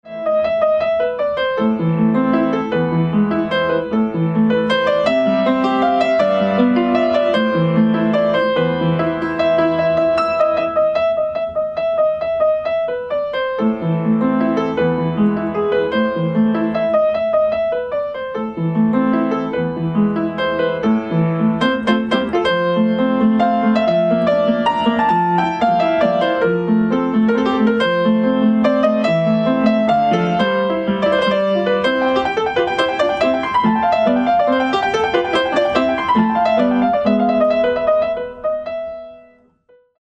Klasyczny